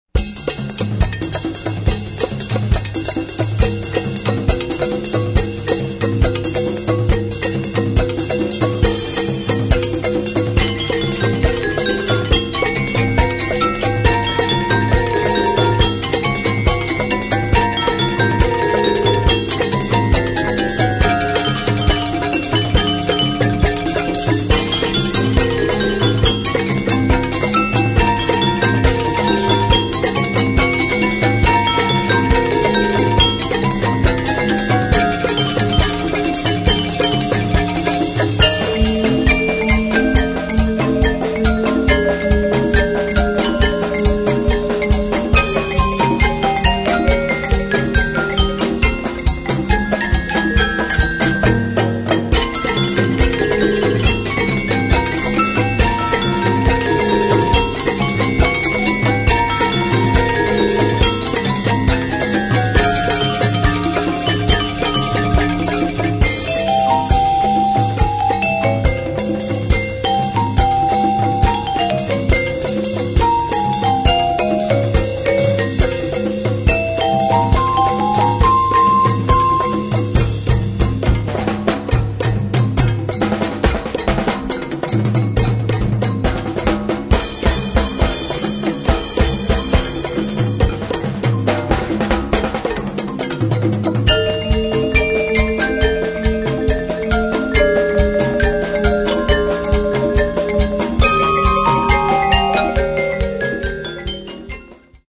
Noten für Schlagzeug/Percussion.